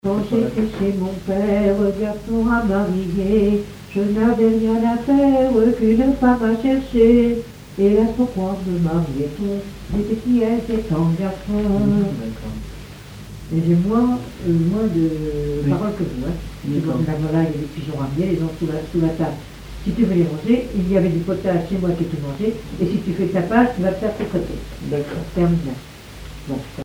Sainte-Hélène-Bondeville
Pièce musicale inédite